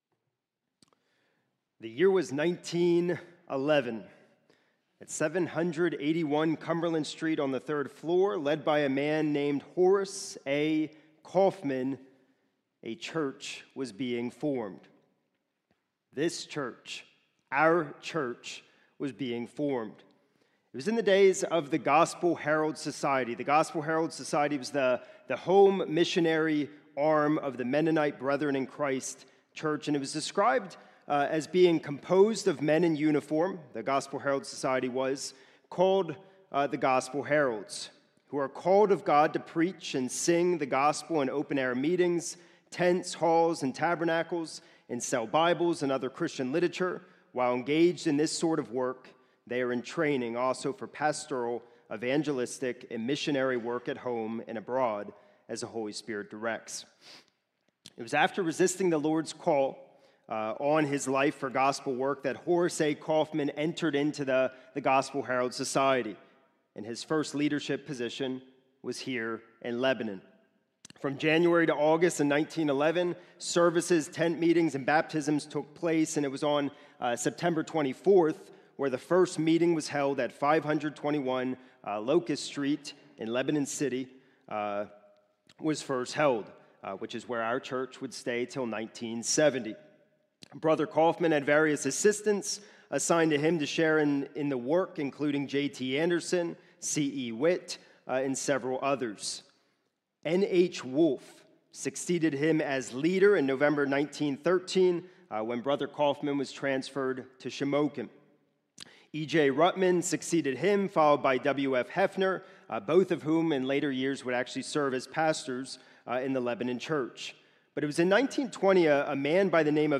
This is a sermon recorded at the Lebanon Bible Fellowship Church in Lebanon, PA during the morning service on 3/29/2026 titled